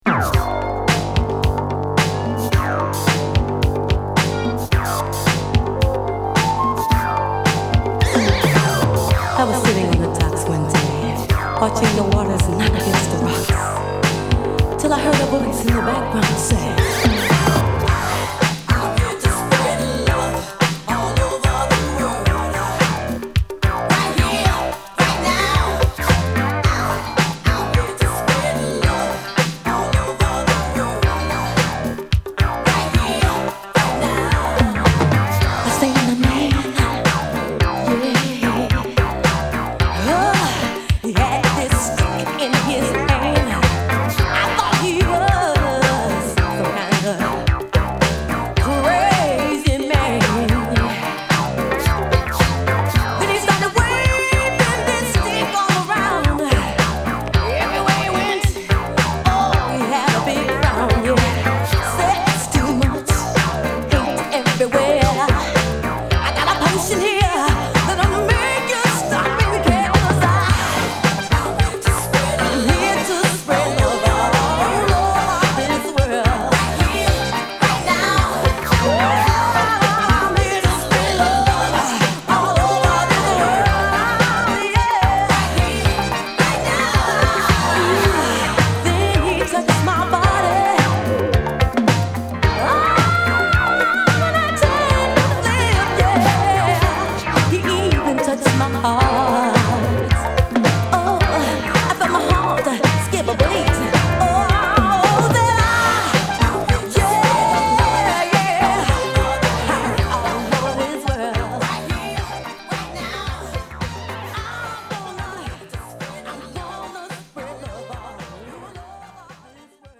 コズミックなエレクトロ感あるシンセプレイにマシーンドラム、ギターが絡むディスコトラックで、女性シンガーをfeat.！